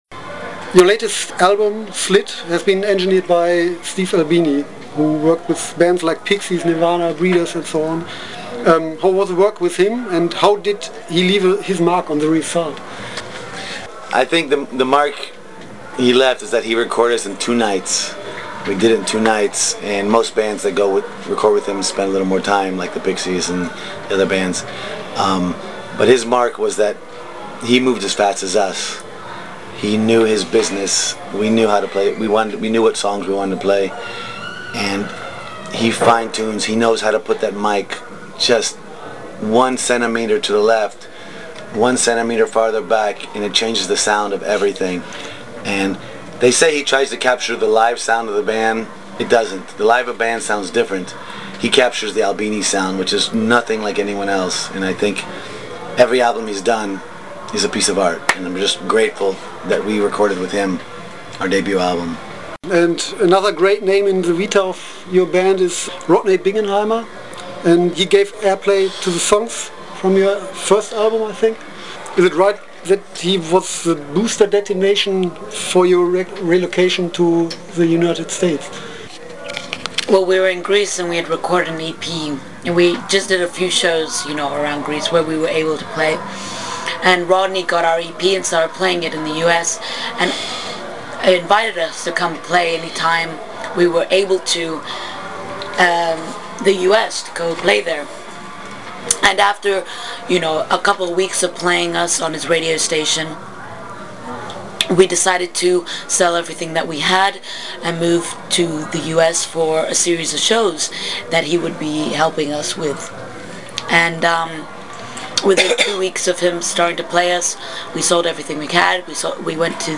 Letzte Episode Barb Wire Dolls 23. Oktober 2013 Nächste Episode download Beschreibung Teilen Abonnieren Barb Wire Dolls sind eine Band aus Los Angeles, die ursprünglich aus Griechenland stammt. Ich hatte die Gelegenheit die Band vor ihrem Auftritt im Tube am 24.10.2013 in Düsseldorf zu interviewen.